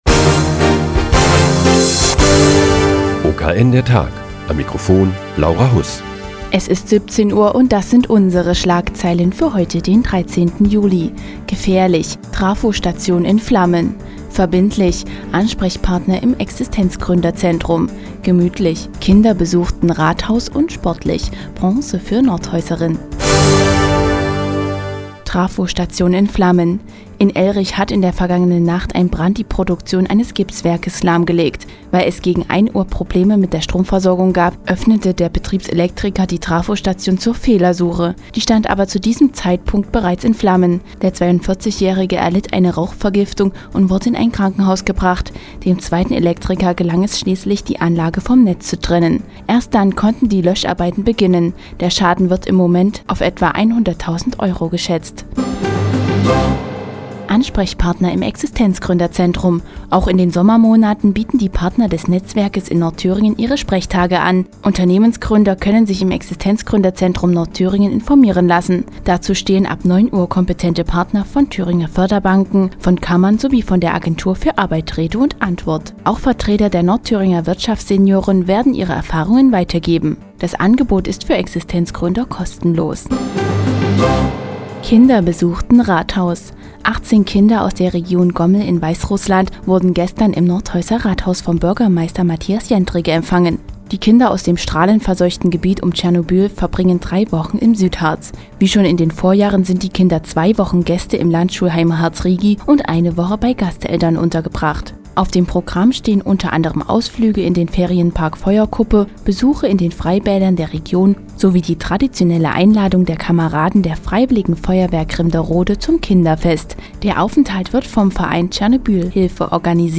Die tägliche Nachrichtensendung des OKN ist nun auch in der nnz zu hören. Heute geht es um einen Brand im Gipswerk in Ellrich und einen Südharz- Besuch von Kindern aus Gomel in Weißrussland.